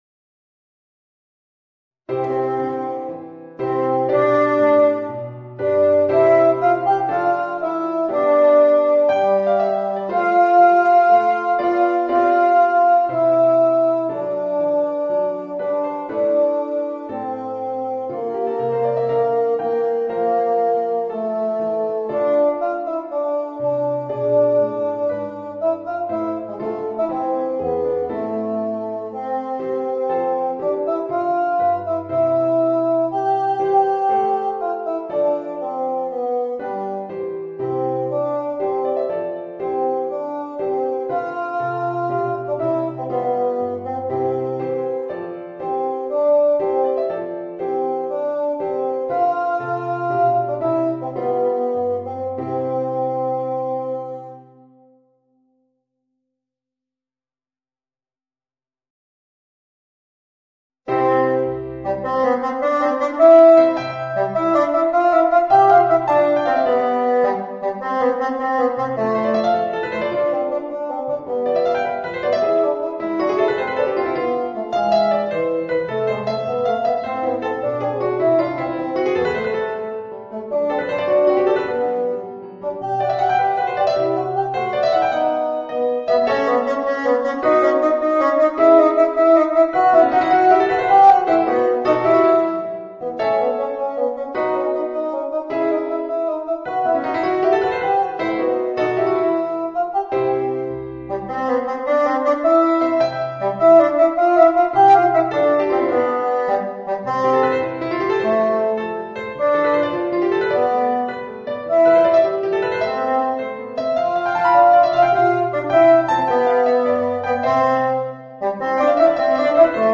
Voicing: Bassoon and Organ